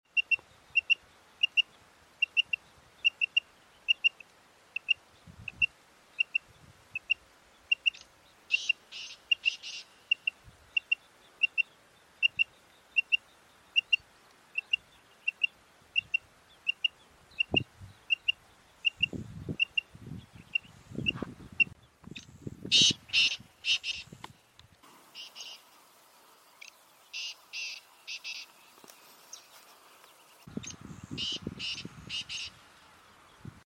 Black Partridge / Black Francolin